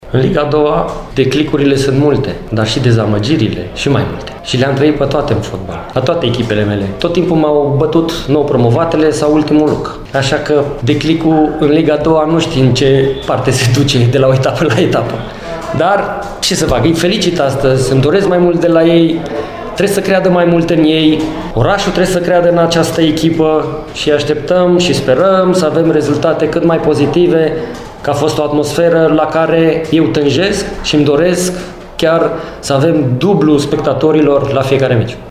Eric Lincar a fost întrebat dacă victoria cu Steaua, cu patru goluri marcate, poate reprezenta un declic: